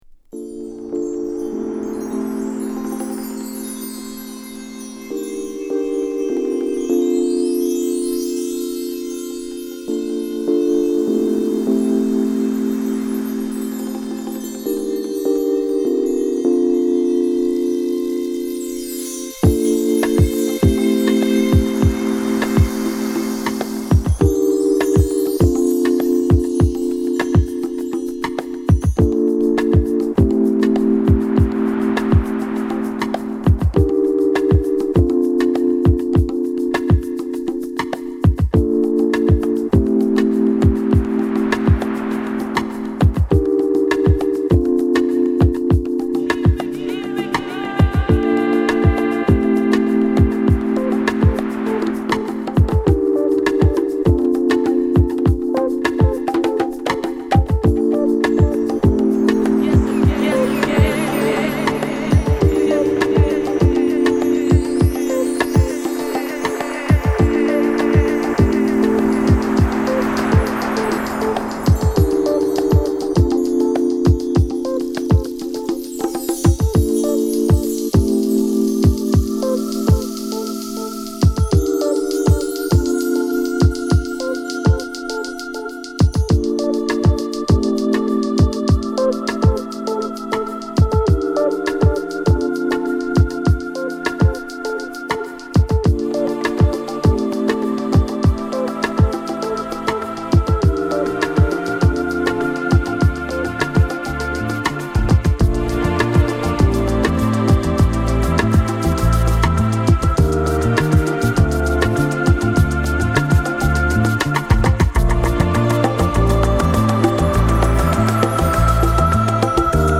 ＊試聴はA「Main Vox」です。